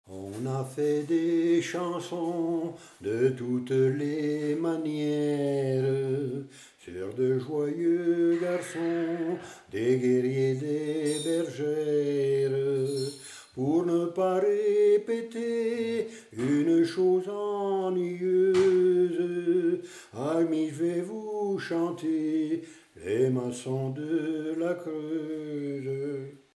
Origine : Limousin (Creuse)